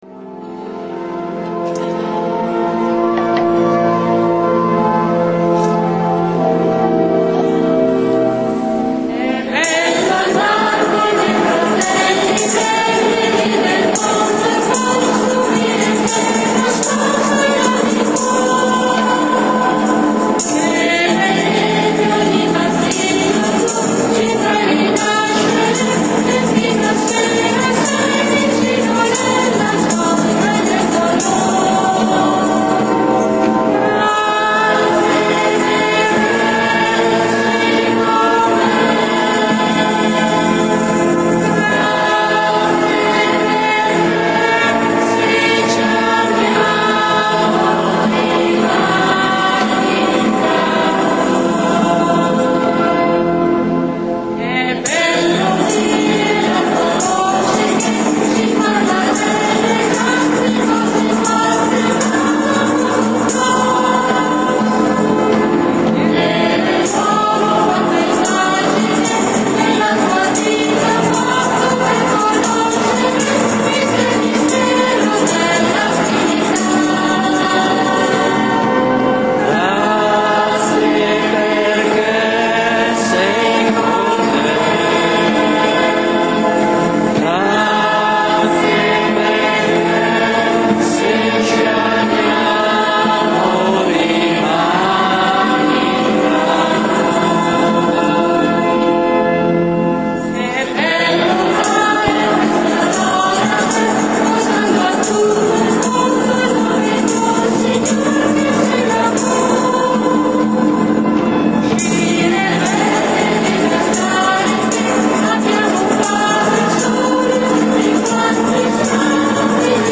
Giornata della Carità
canto: